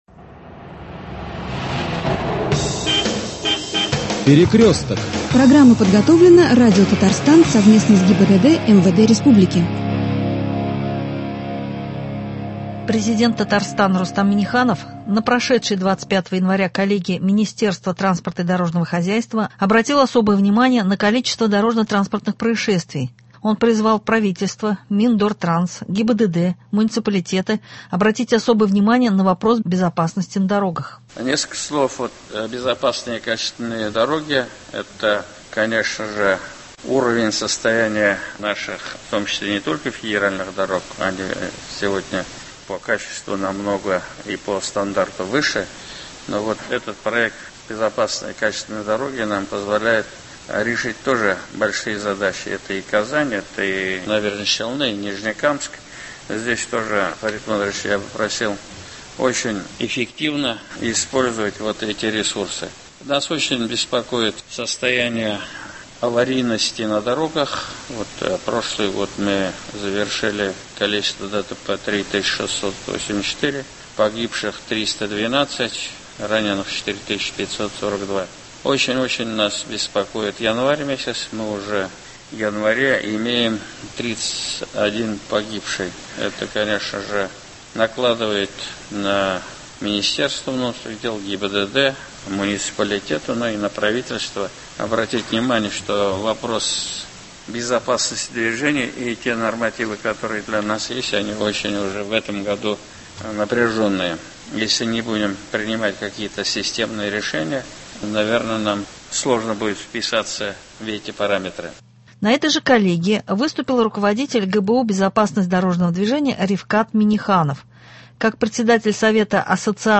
На этой же коллегии выступил руководитель ГБУ БДД Рифкат Минниханов.